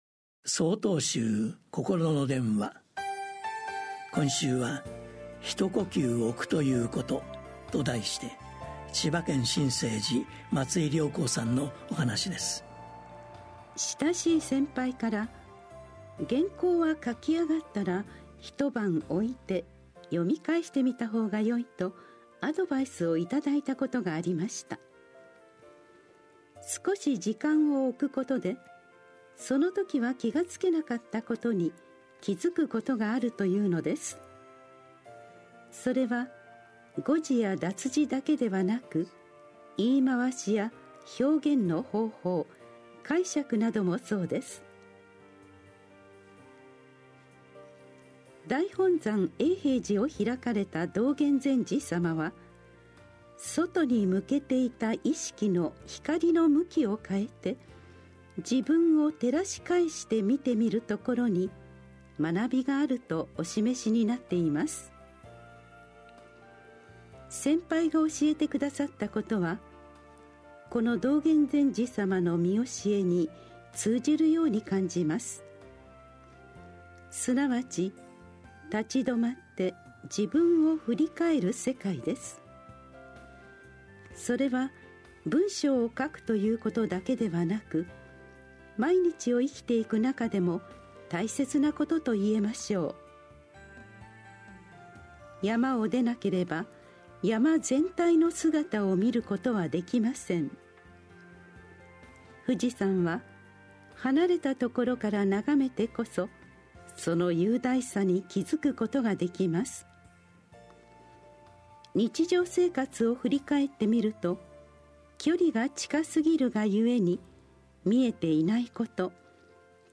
心の電話（テレホン法話）７/15公開『ひと呼吸おくということ』 | 曹洞宗 曹洞禅ネット SOTOZEN-NET 公式ページ